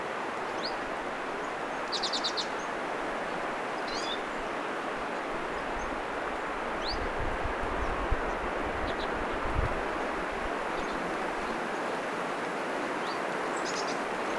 tiltalttilintu joka äänteli tulit.
tuvit-tiltaltti, 2
tuvit-tiltaltti_mika_laji_tama_olikaan_2.mp3